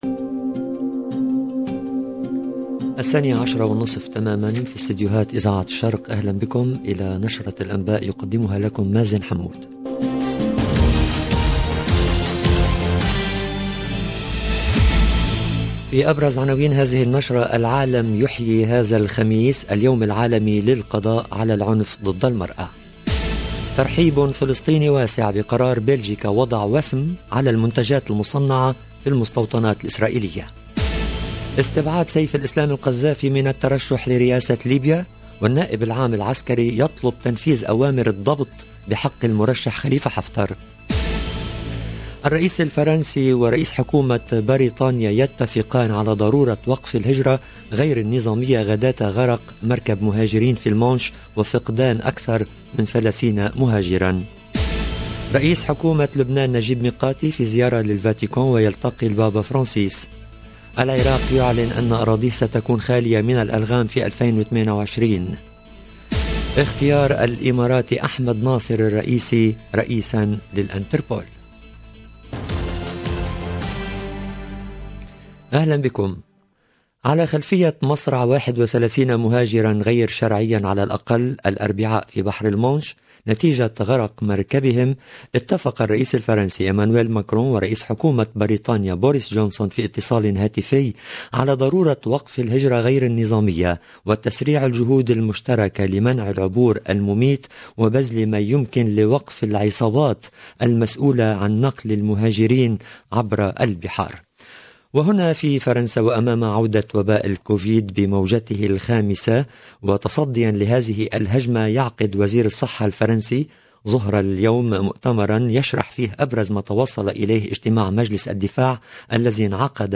LE JOURNAL DE MIDI 30 EN LANGUE ARABE DU 25/11/21